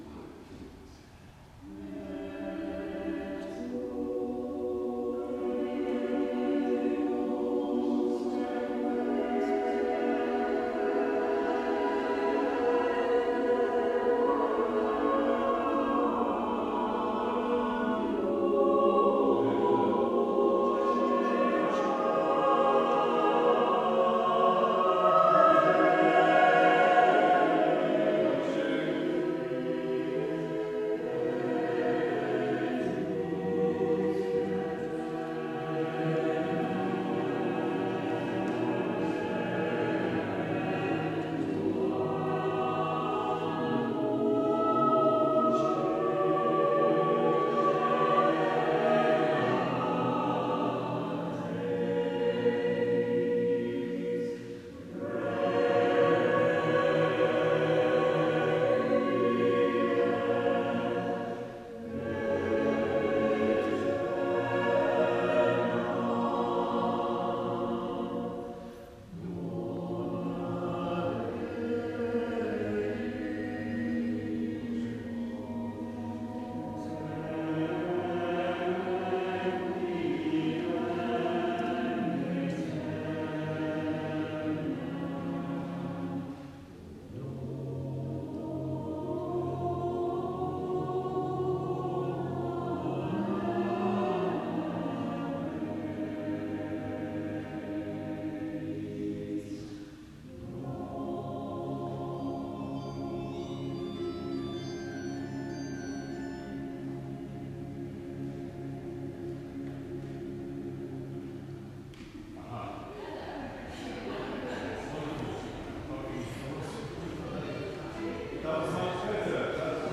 In the table below you will find video and audio recordings of the choir, latest first.